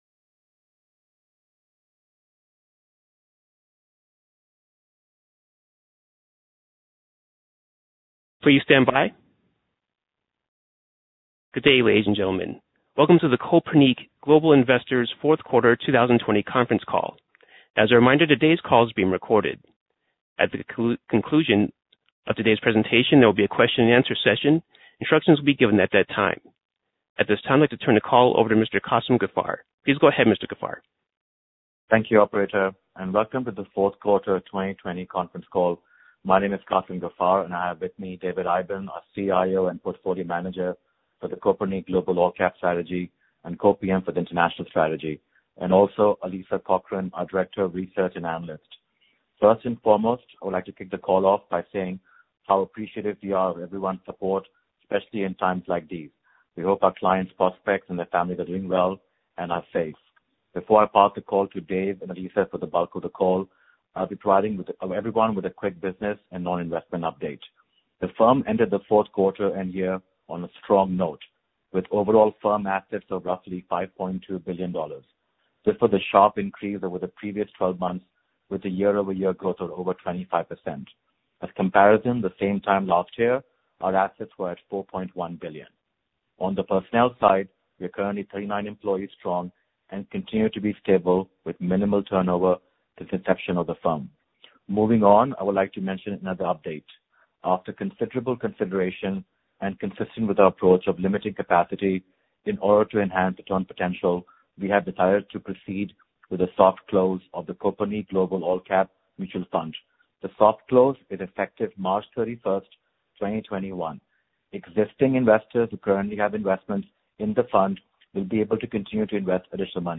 Q4 2020 Conference Call - Kopernik Global Investors